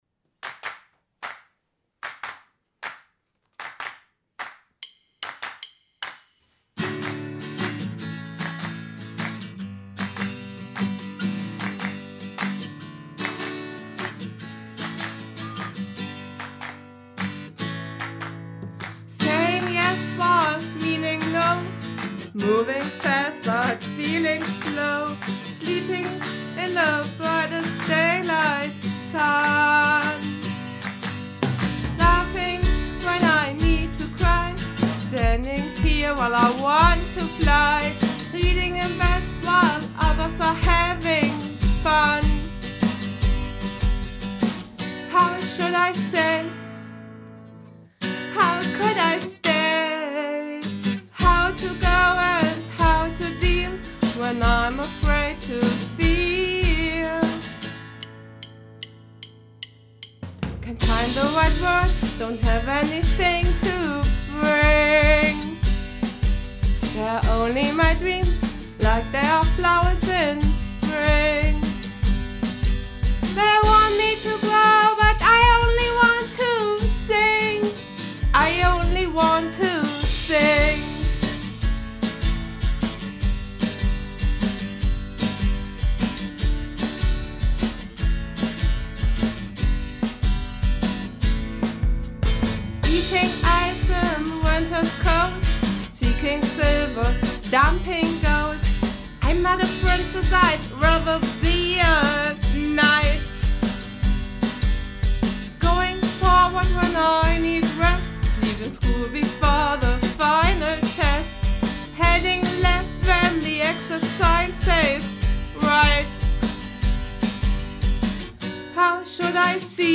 Der Song ist eine Demo bisher. Es wird noch gebastelt und probiert.
Leider nur am PC, aber ich habe keine Band, die ich fragen könnte.